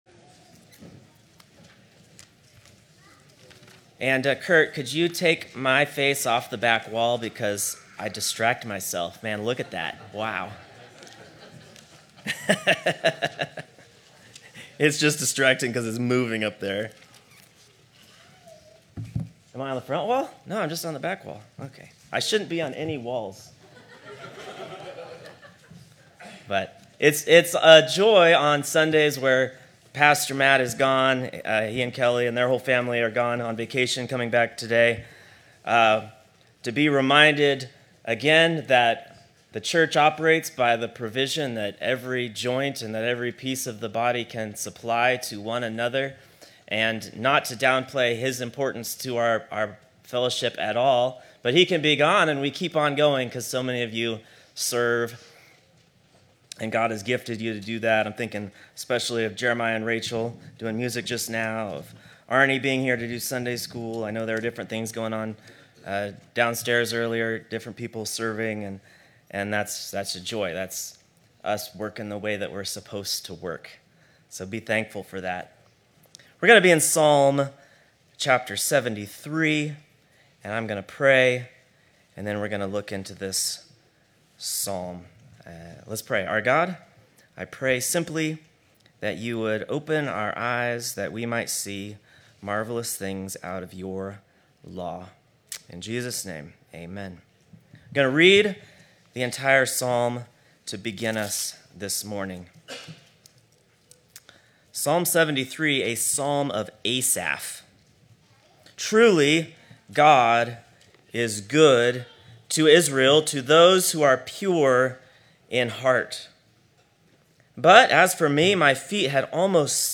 One Off Sermons Passage: Psalm 73 Service Type: Morning Service Whom have I in heaven but you?